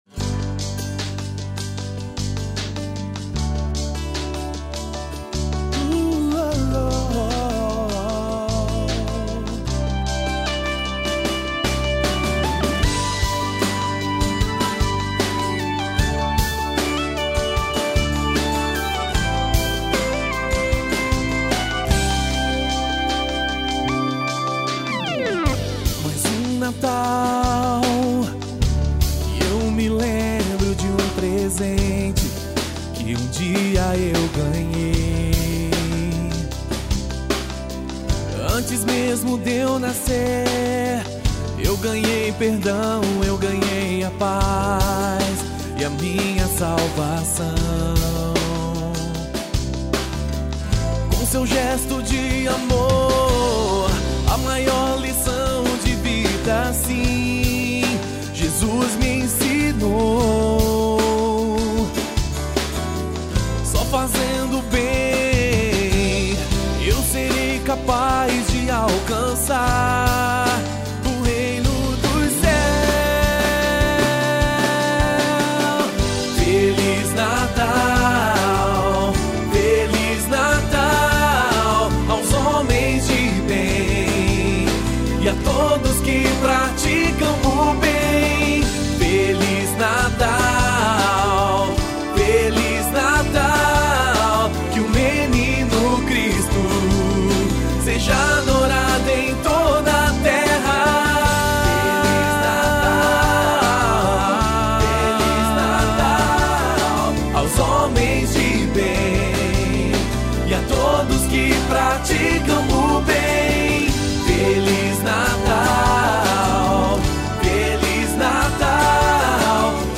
Composição, instrumentos, samplers,
back-vocal e voz principal